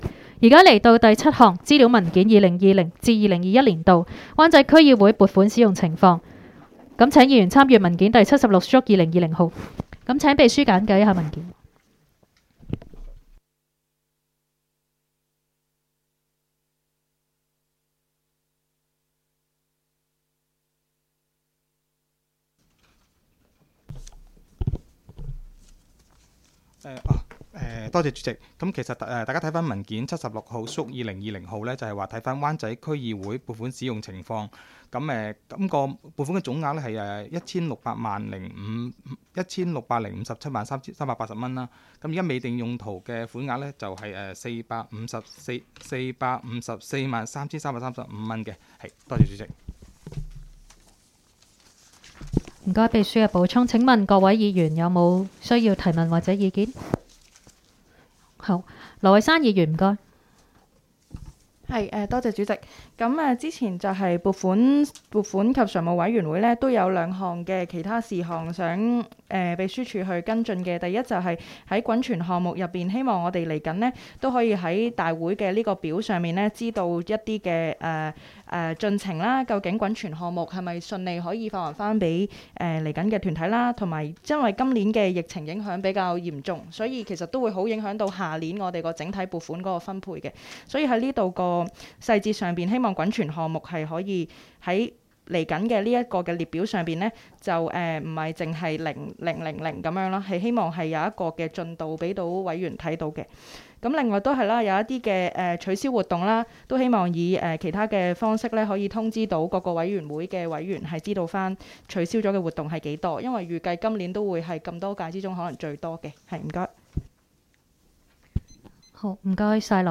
湾仔区议会会议室